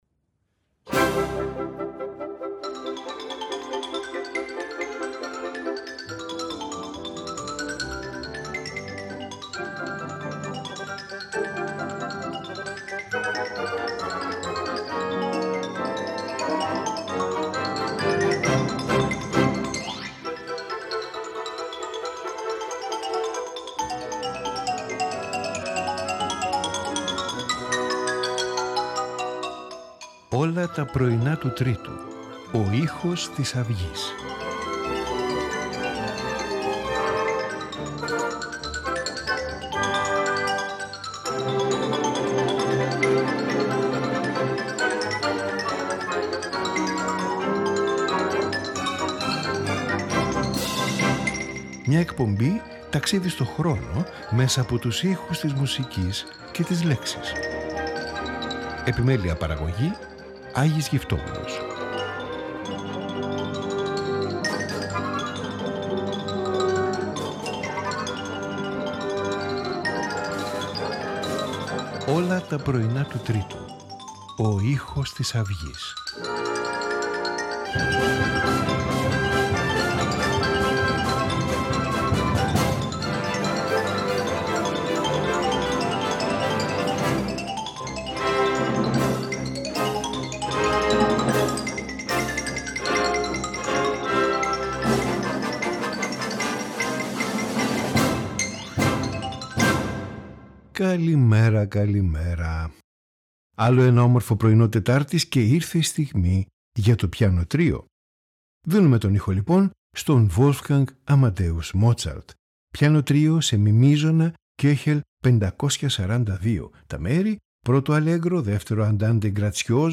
Σήμερα το πιάνο έχει την τιμητική του…